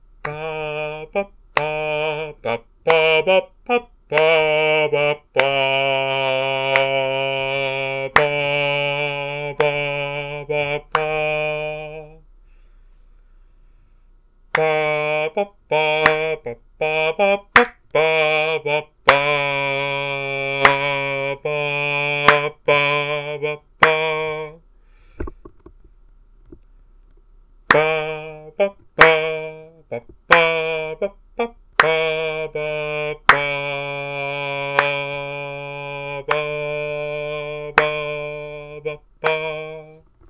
If you were to try to put this into a 4/4 for example you’d either get a highly syncopated feel or you’d need to match the durations to the different meter, such as this:
No matter what you do, the duct and thus the quality of the melody changes.